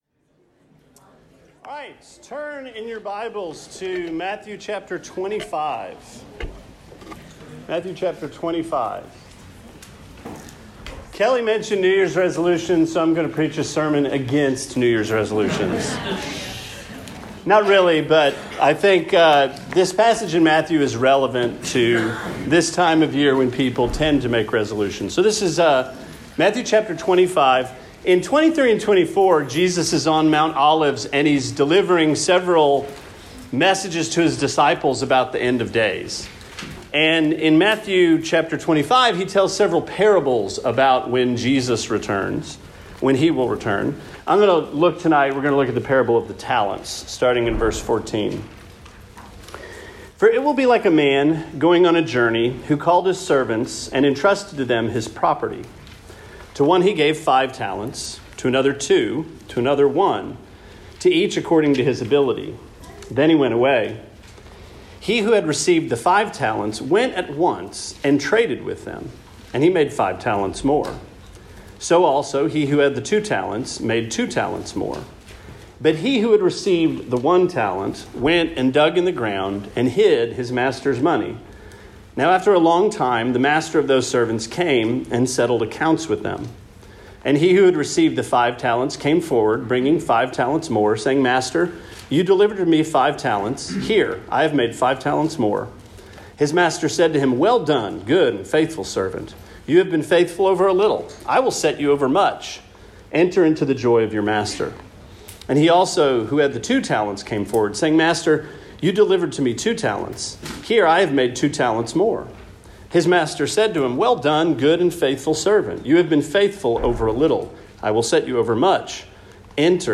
Sermon 01/03: Matthew-Parable of the Talents – Trinity Christian Fellowship